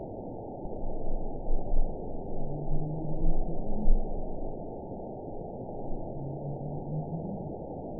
event 910330 date 01/18/22 time 14:24:47 GMT (3 years, 5 months ago) score 6.46 location TSS-AB07 detected by nrw target species NRW annotations +NRW Spectrogram: Frequency (kHz) vs. Time (s) audio not available .wav